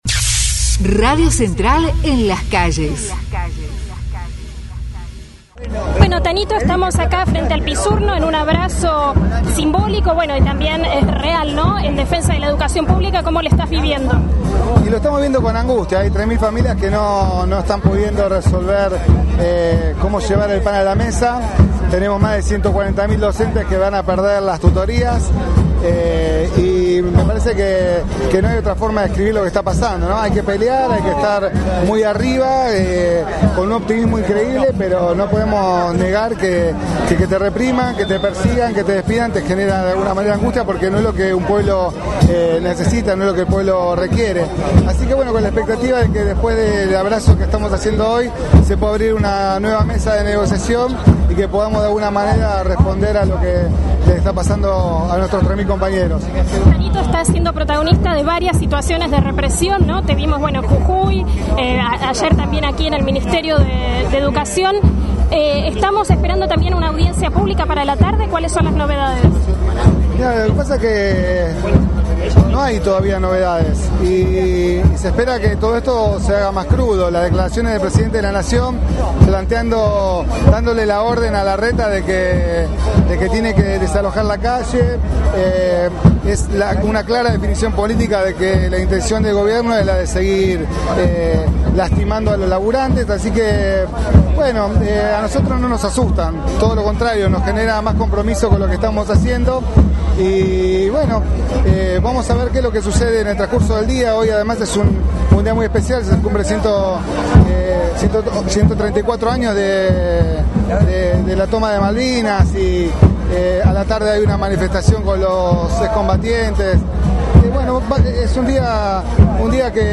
Protesta en el Ministerio de Educación